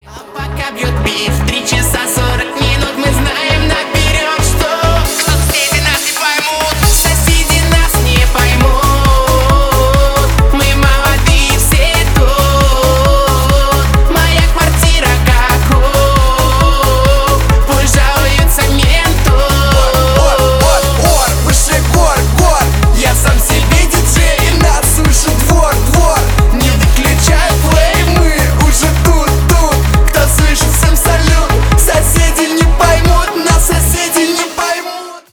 громкие , поп